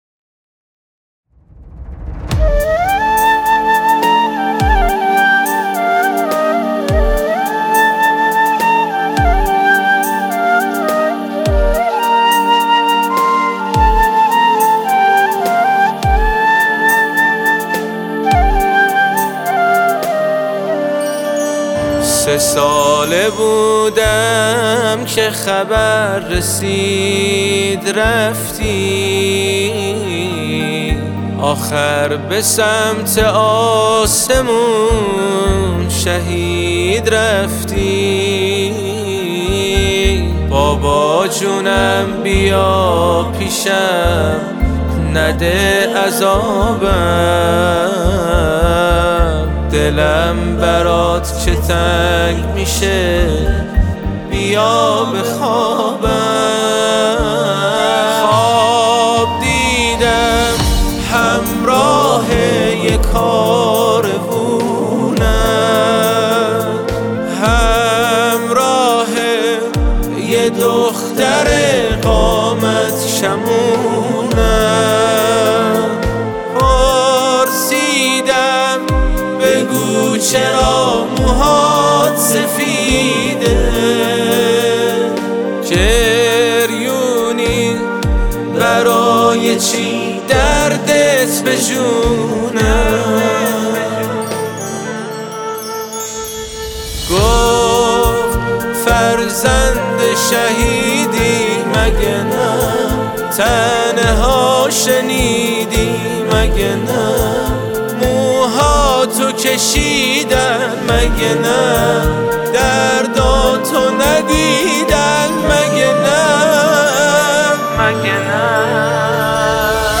تهیه شده در استودیو نجوا